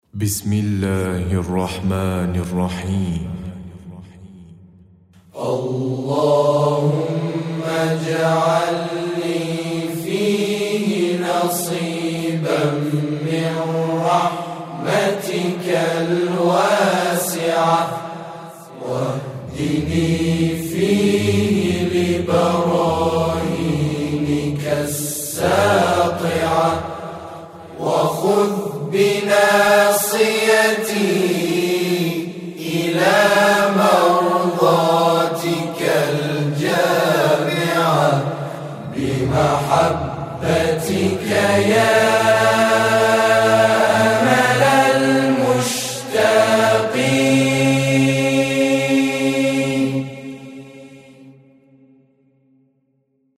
نماهنگ و سرود رسمی و معنوی